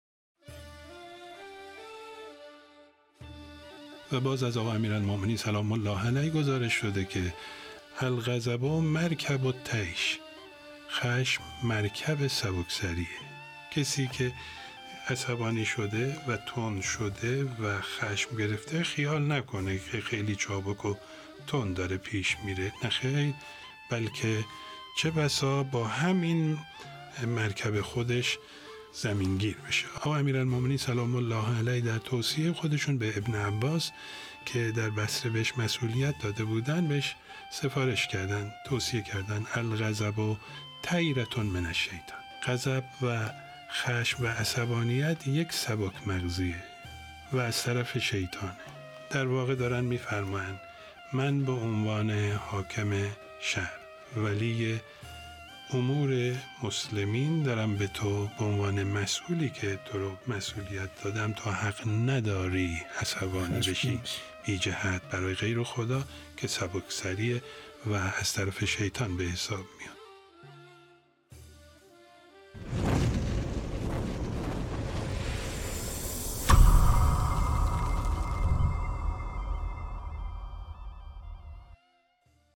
درس اخلاق | یک پیام هشدارآمیز از أمیرالمؤمنین (ع)